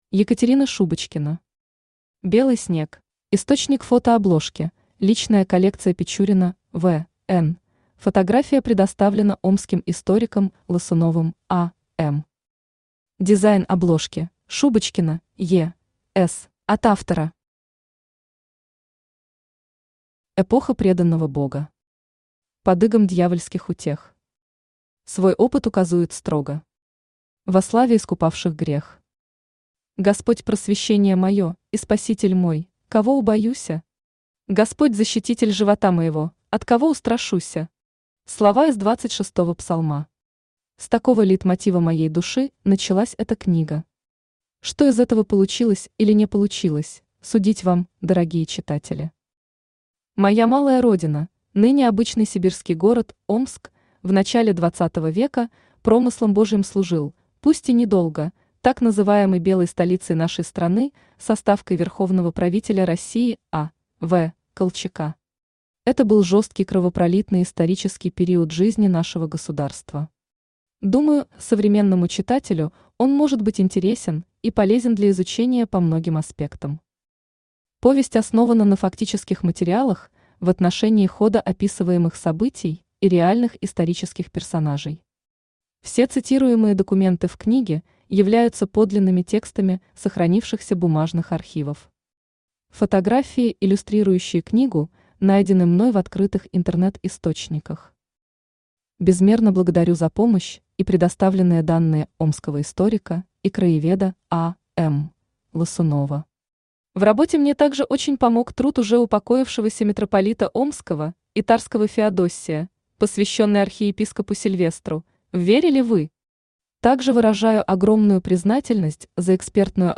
Aудиокнига Белый снег Автор Екатерина Шубочкина Читает аудиокнигу Авточтец ЛитРес.